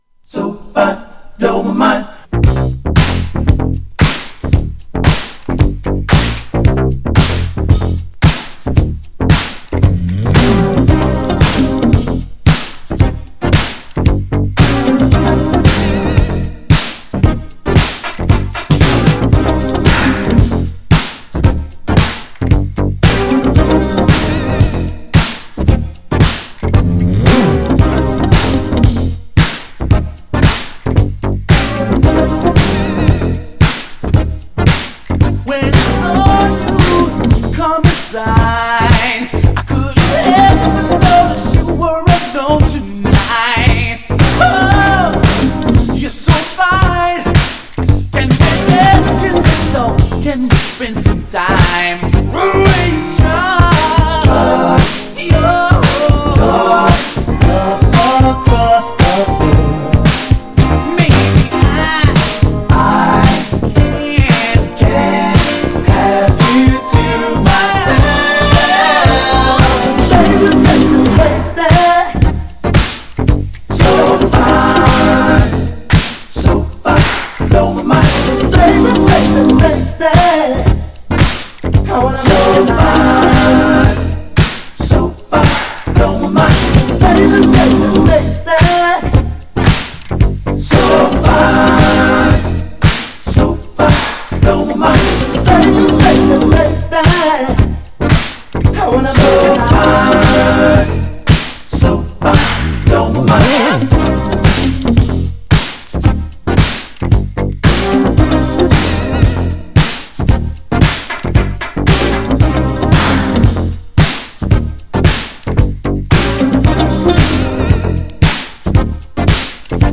人気の80's DISCO!!
GENRE Dance Classic
BPM 111〜115BPM
エモーショナル # エレクトロ # ダビー # ブラコン